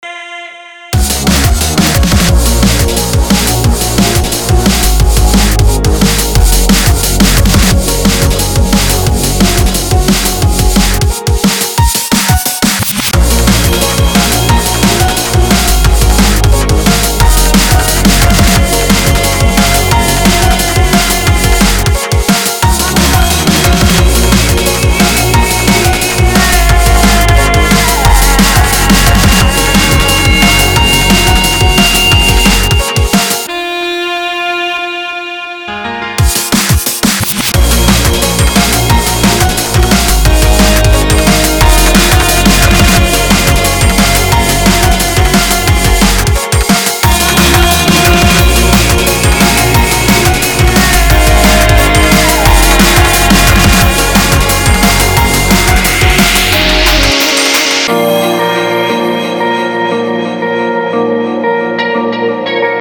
• Качество: 320, Stereo
громкие
атмосферные
женский голос
энергичные
быстрые
Liquid DnB
Стиль: Liquid Drum